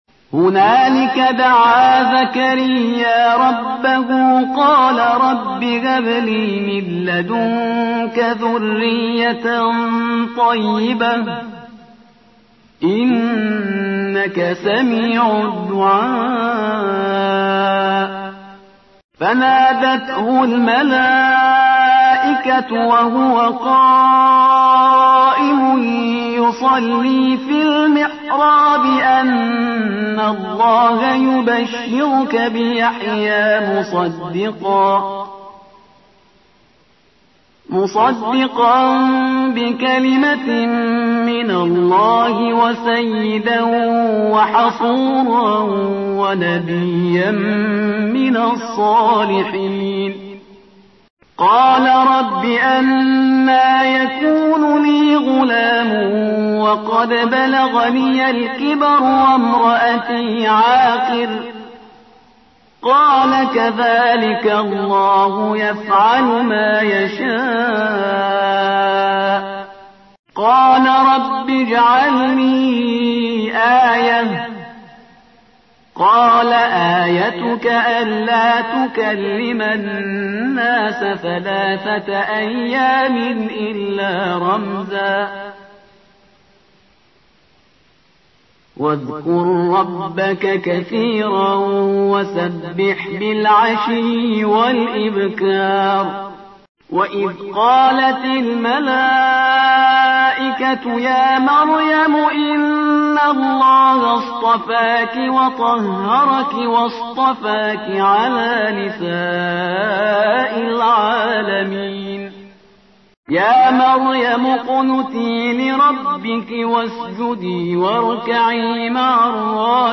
ترتیل صفحه ۵۵ سوره مبارکه آل عمران با قرائت استاد پرهیزگار(جزء سوم)